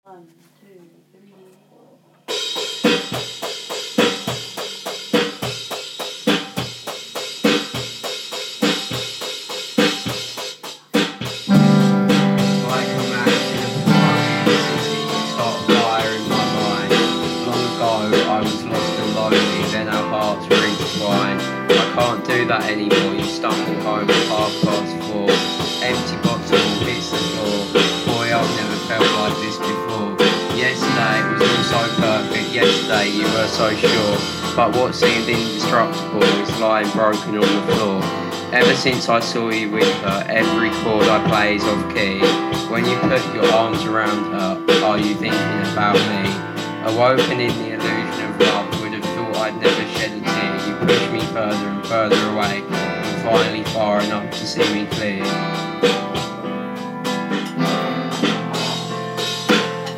drums
electric guitar
bass
singing
piano